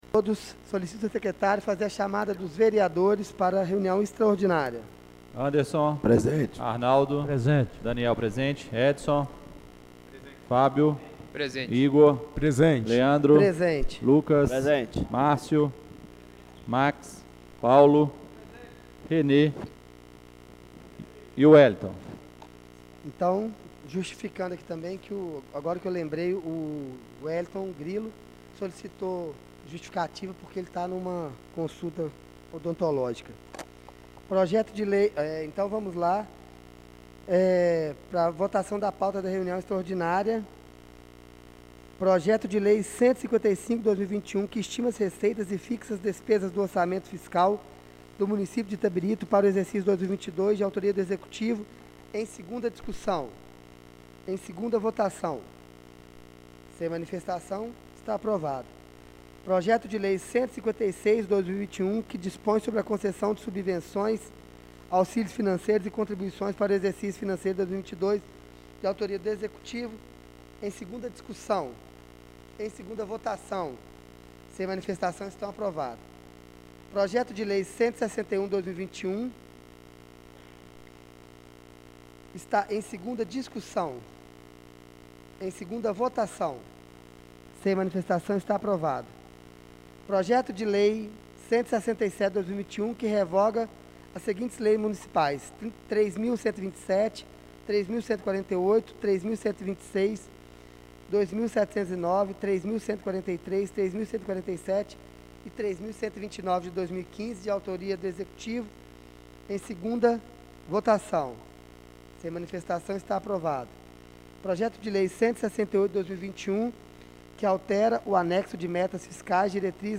Reunião Extraordinária do dia 16/11/2021